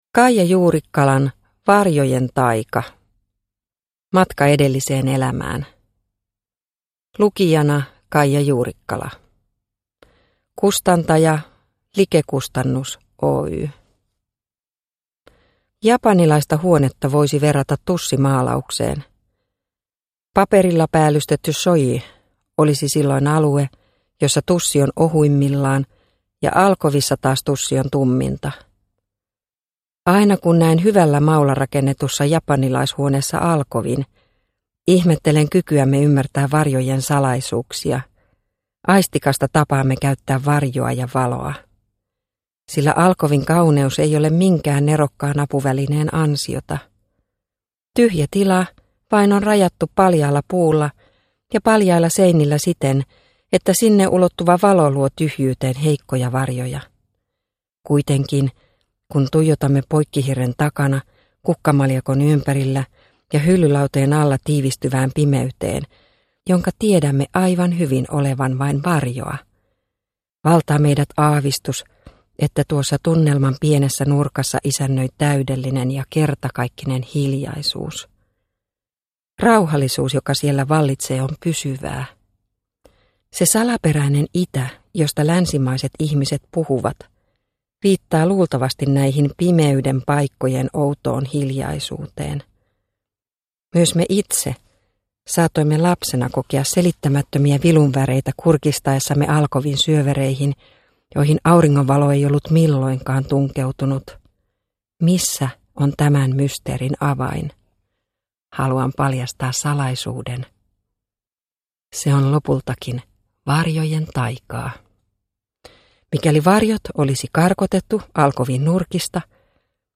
Varjojen taika (mp3) – Ljudbok – Laddas ner